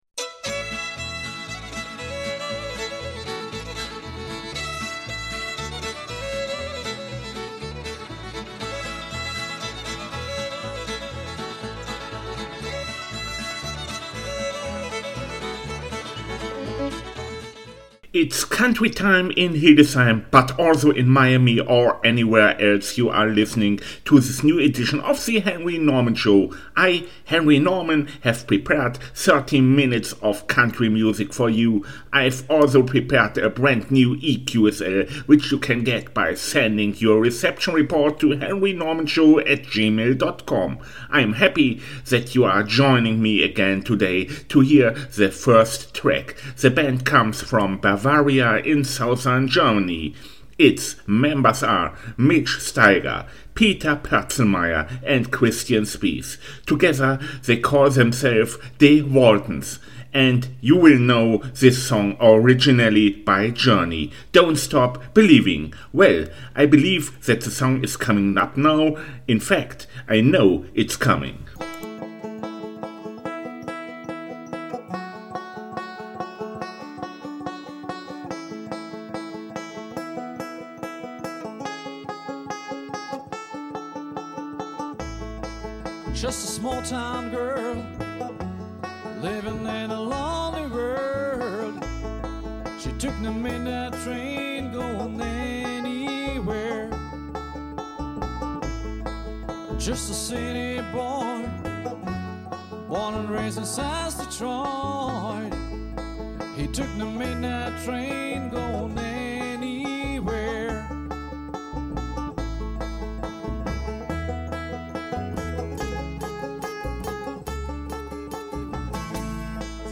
This week with Countrymusic